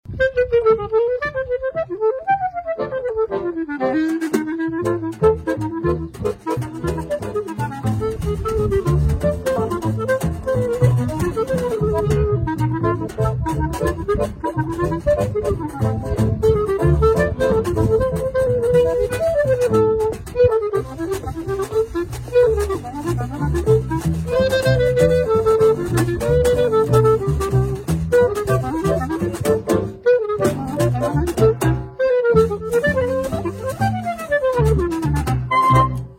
Saxofon/Klarinette